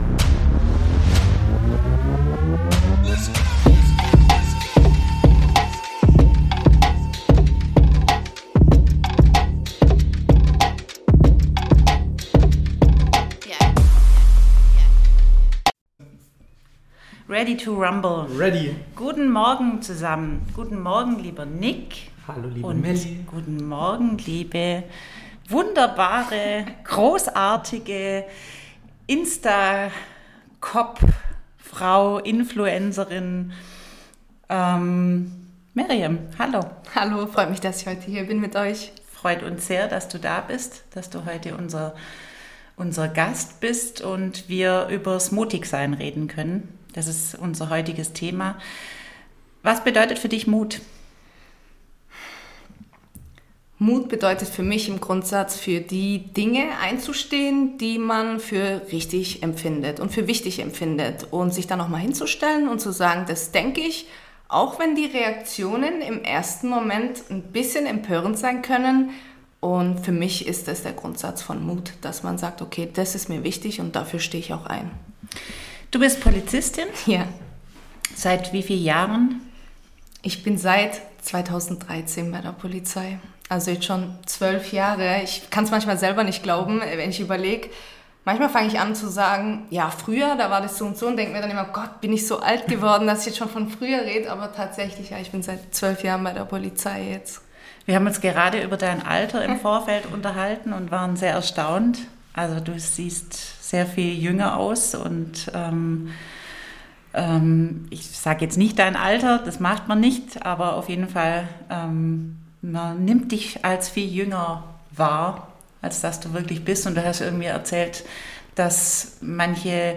Es wird ehrlich, witzig und wie immer aus dem Grund mutig, dass wir als Podcastler unsere Aufzeichnungen nicht schneiden.